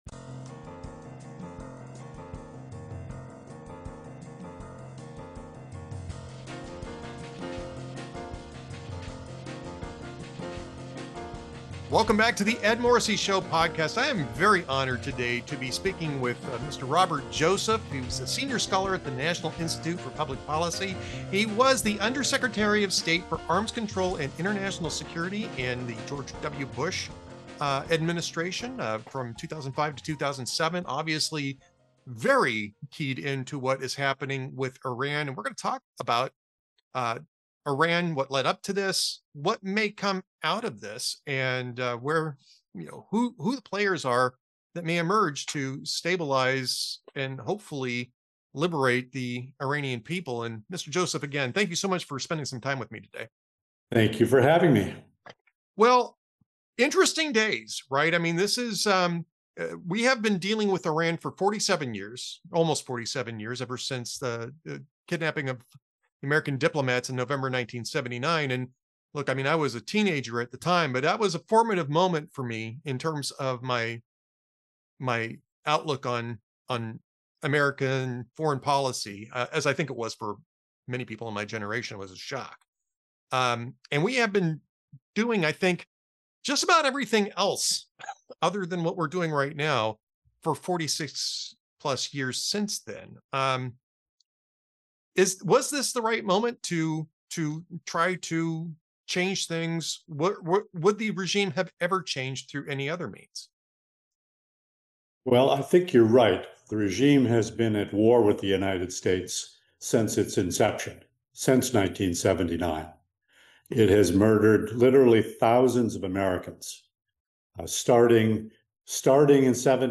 He joins me in discussing the failure of appeasement, the necessity of Operation Epic Fury, and why the NCRI has a seat at the table when the IRGC and its regime finally implodes – if Donald Trump stays the course.